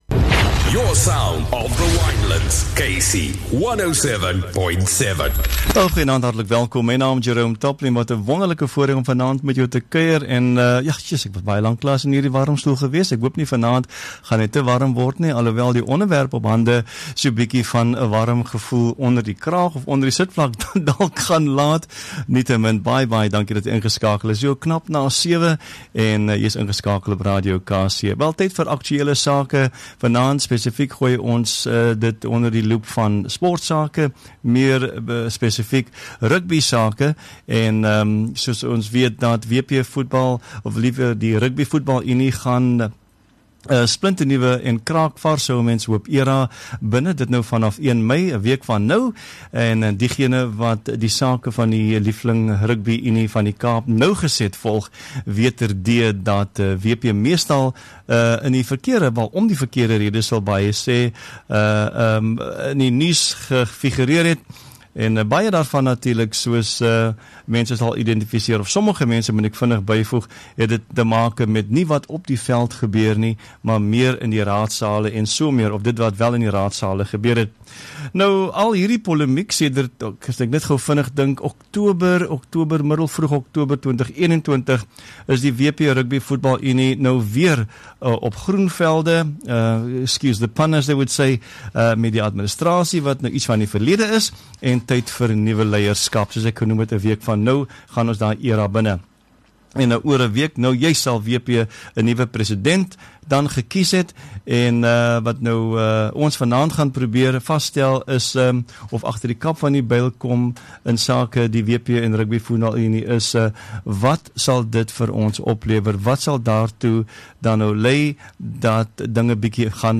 24 Apr WP Presidential Debate.